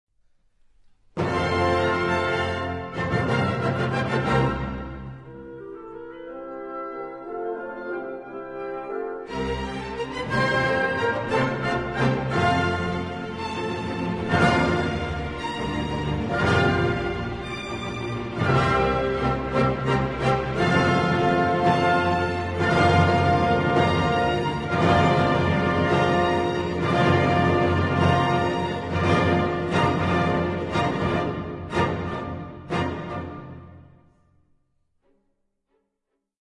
Symphony No. 8 in F Op. 93